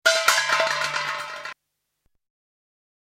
ジャンジャラジャーン（48KB）